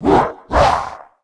Sound / sound / monster / recycle_monster / attack_2.wav
attack_2.wav